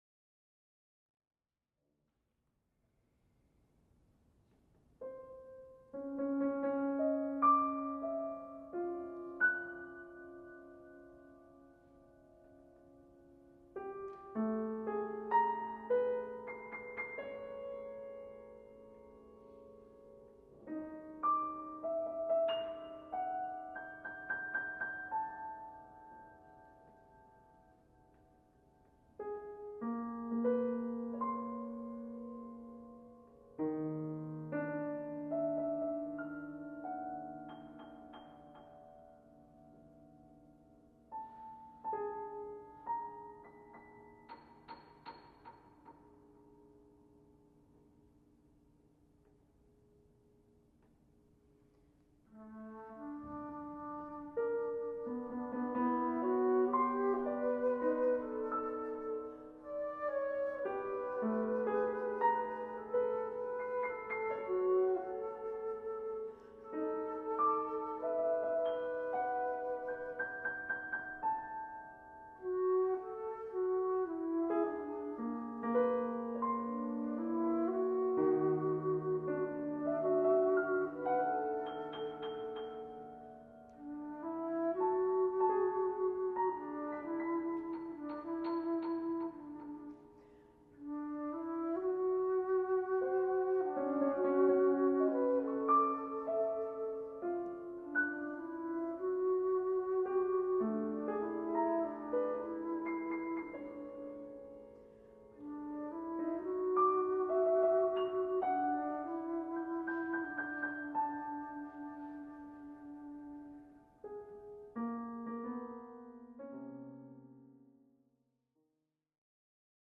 for alto flute and piano
alto flute
piano
shenandoah-transposed-excerpt.mp3